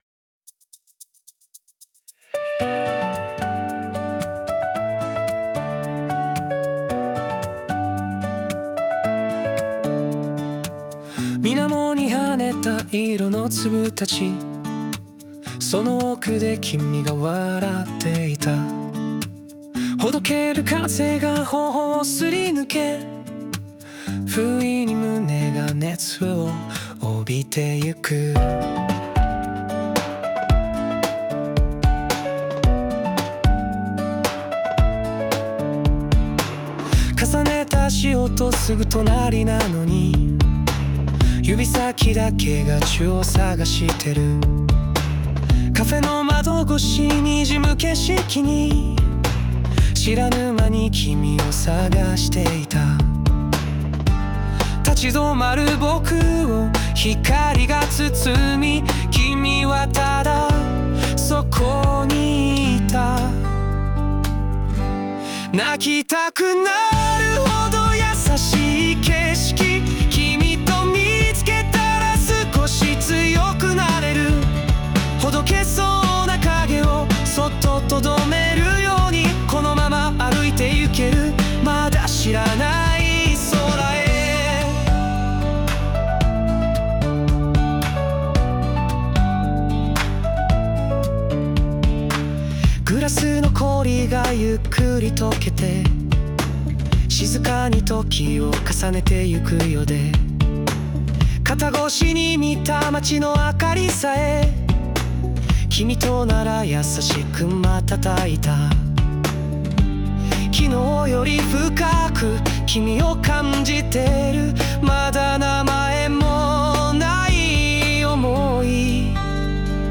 男性ボーカル邦楽邦楽 男性ボーカル作業BGMポップス切ない
著作権フリーオリジナルBGMです。
男性ボーカル（邦楽・日本語）曲です。
たまには少し切ない系のラブソングを💛